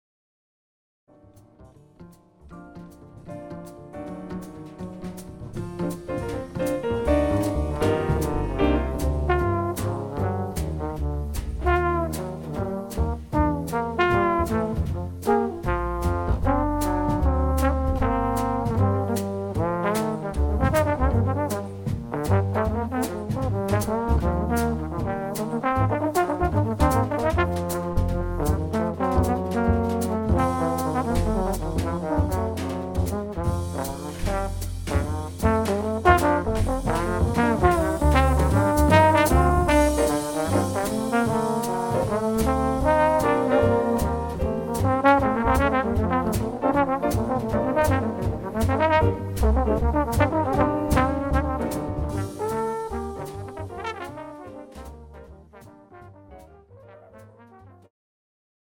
The Best In British Jazz
Recorded at Norden Farm Centre for the Arts, Jan 9th 2014